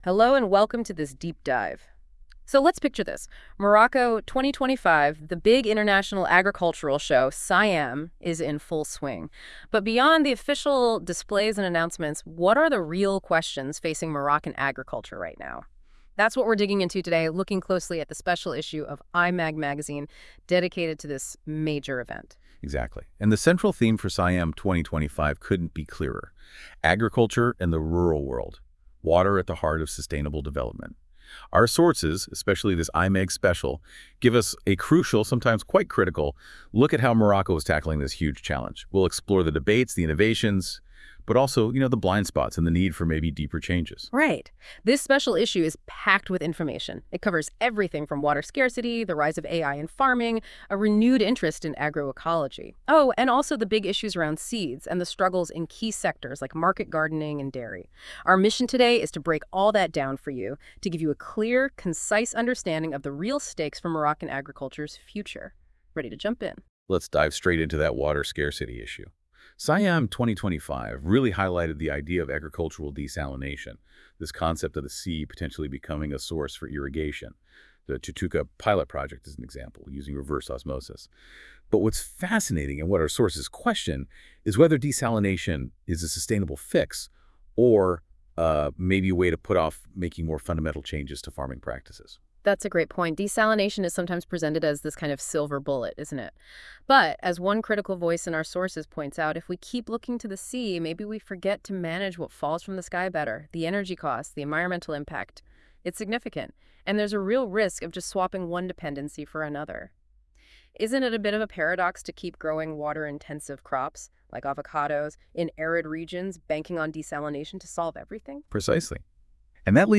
Les chroniqueurs de la Web Radio R212 ont lus attentivement le magazine de L'ODJ Média et ils en ont débattu dans ce podcast IMAG Spécial SIAM 2025.wav (25.31 Mo)